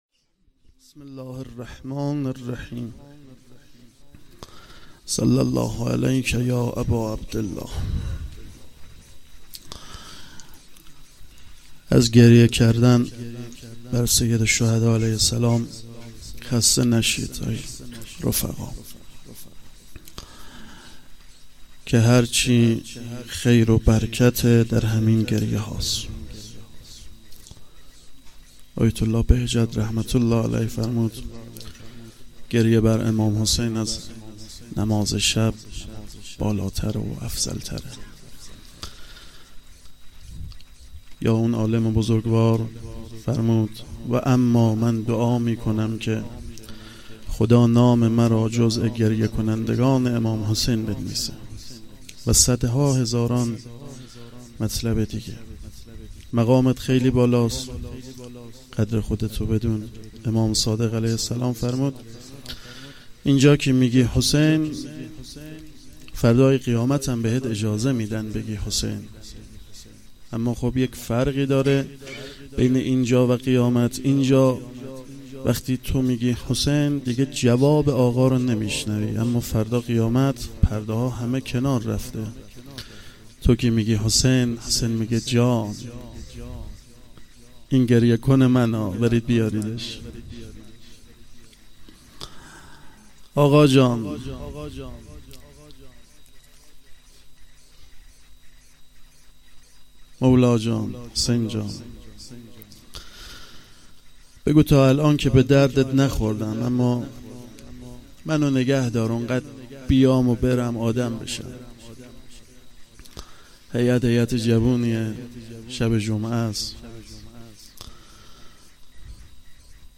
هیئت فرهنگی مذهبی فاطمیون درق
روضه